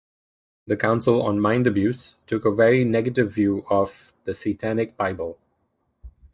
Pronounced as (IPA) /seɪˈtænɪk/